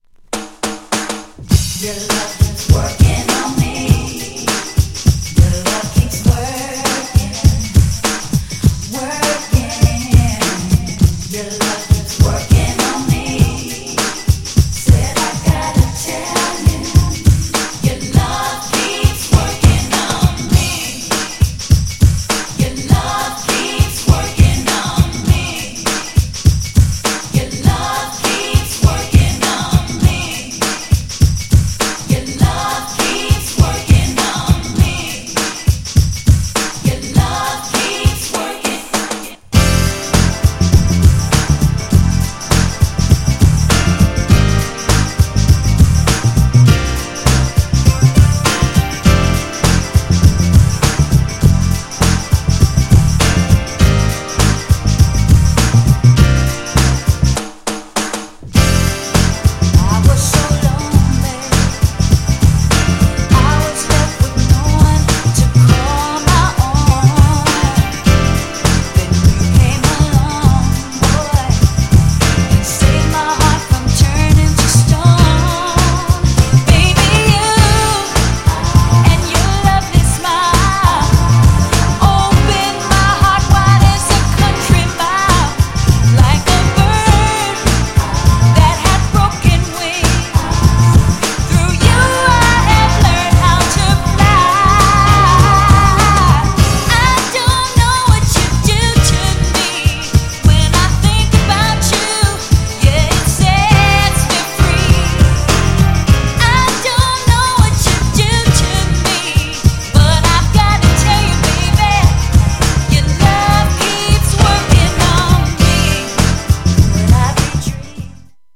タフな
GENRE House
BPM 111〜115BPM